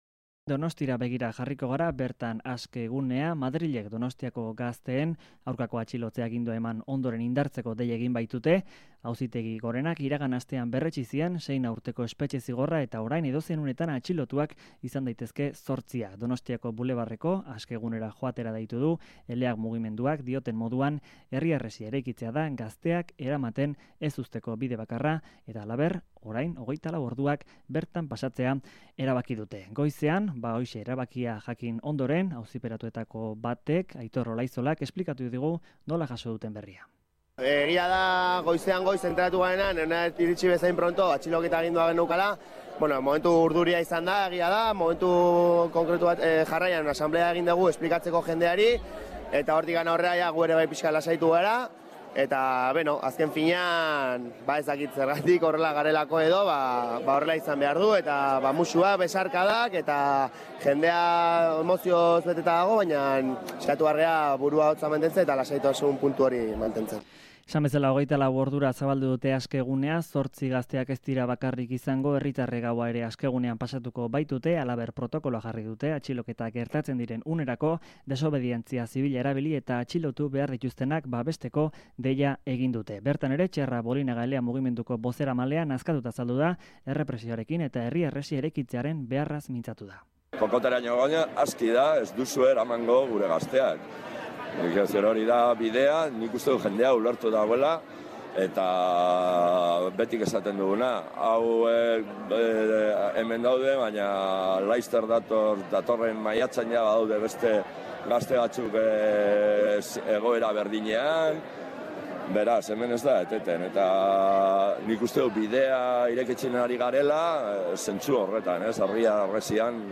Donostiako zortzi gazteen aurkako espetxeratze agindua iritsi denean aske gunea indartzeko deia egin dute. Egunean zehar Aske gunean bildutako iritziak bildu ditugu kronika honetan.